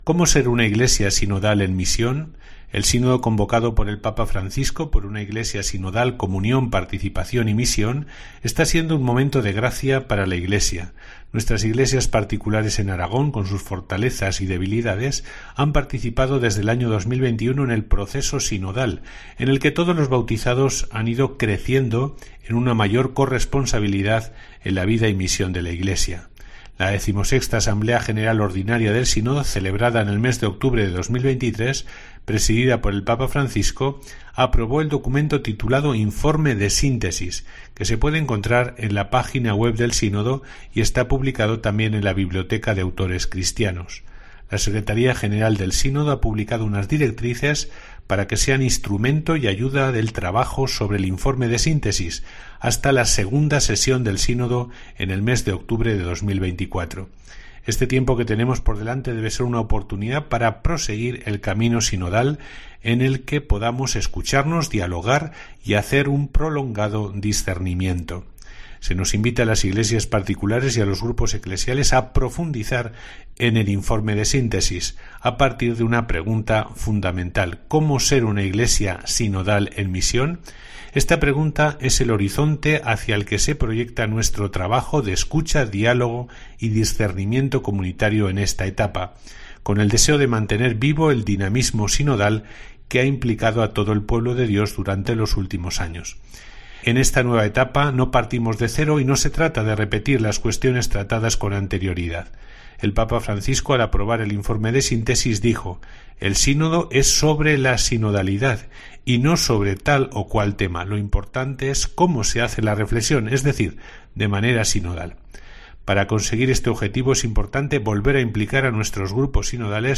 El obispo de Teruel y Albarracín, Monseñor Satué, sobre la carta pastoral de la Iglesia aragonesa